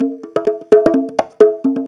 Conga Loop 128 BPM (20).wav